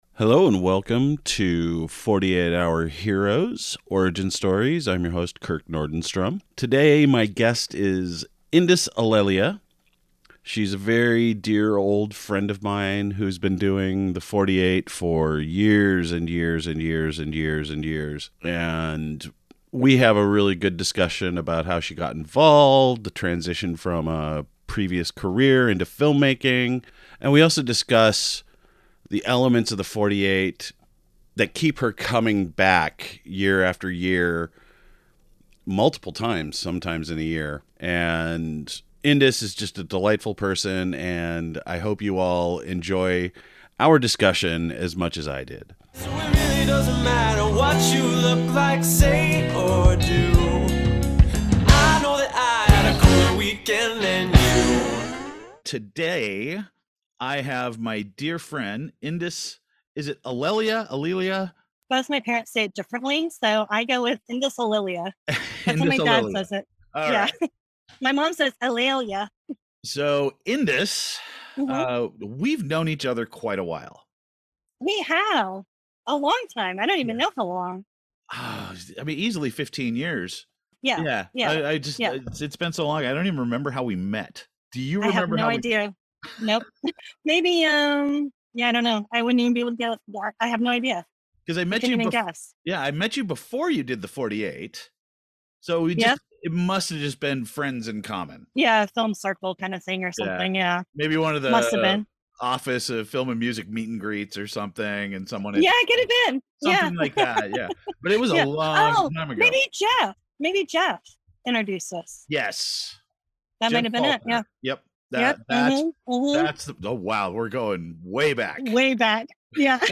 A behind-the-scenes look at the wild world of 48-hour filmmaking, the 48 Hour Heroes podcast features interviews with filmmakers, crew, and special guests who live to create under pressure. From war stories to workflow tips, its an honest, funny, and inspiring celebration of creativity on the clock.